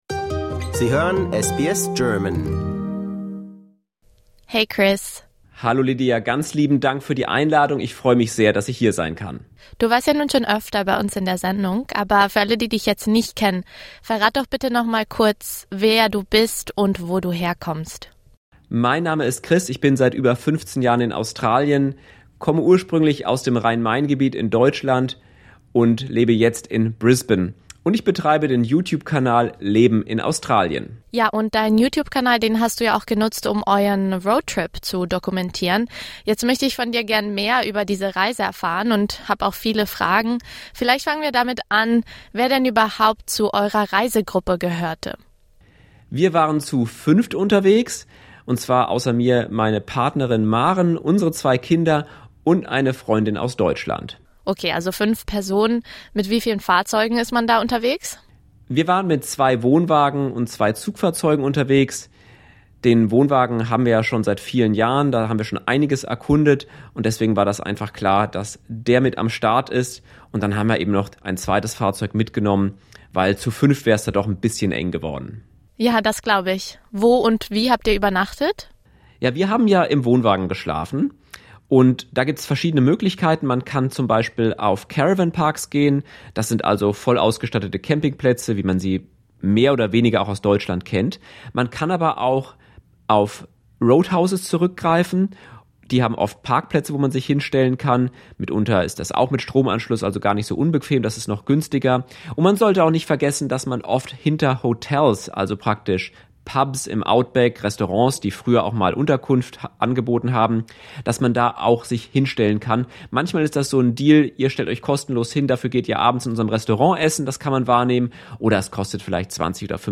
For more stories, interviews, and news from SBS German, discover our podcast collection here.